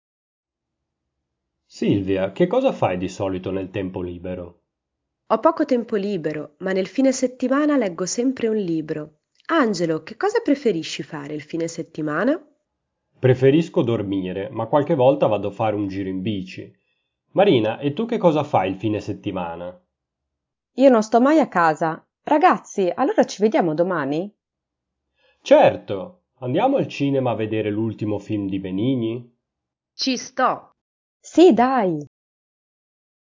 Lezione 13 - Il Tempo Libero / Materiais Outros MP3 Audio - Dialogo - Lezione 13.mp3 cloud_download MP3 Audio - Lezione 13.mp3 cloud_download PDF Material PDF - Lezione 13.pdf cloud_download